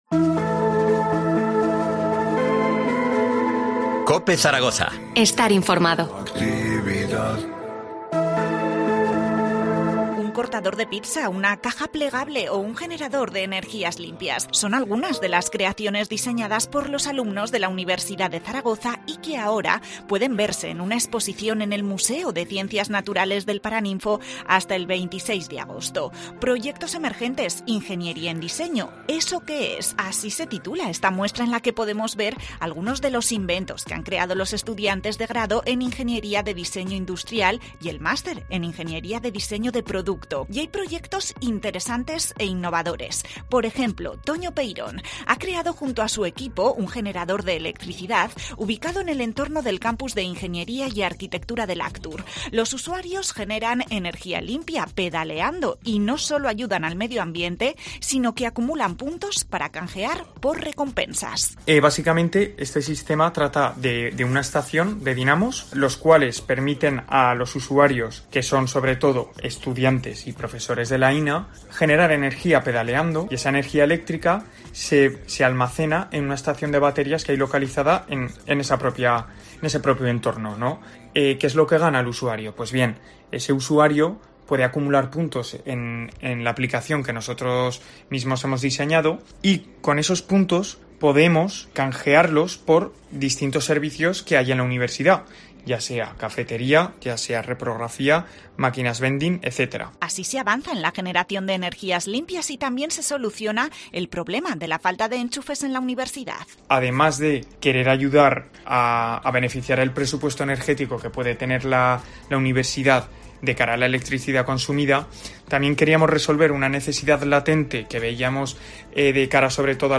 Hablamos con los universitarios que protagonizan la exposición “Proyectos Emergentes: Ingeniería en Diseño"